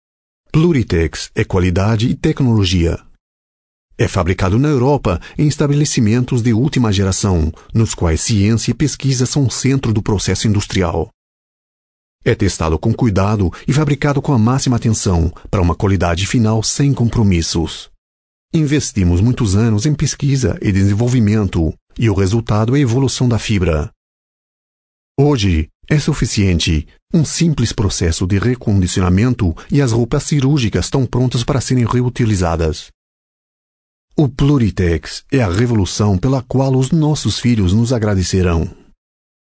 Native speaker Male 30-50 lat